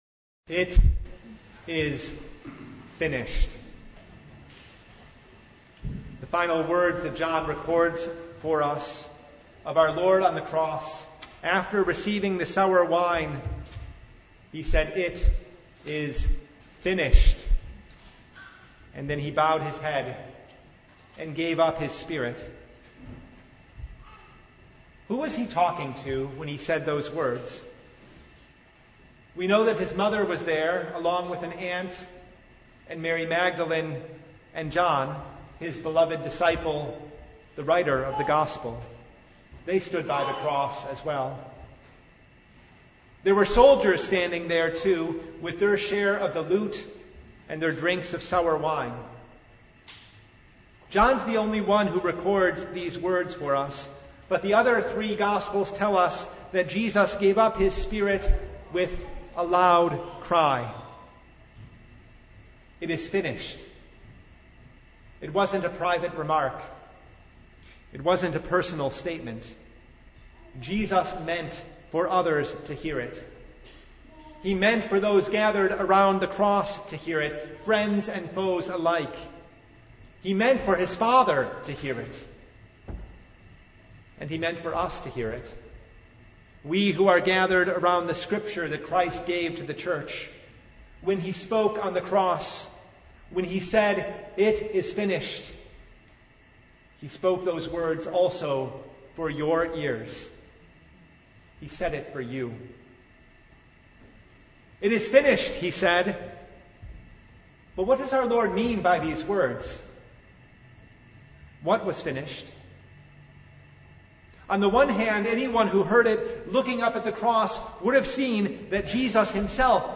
John 19:30 Service Type: Good Friday Noon “It is finished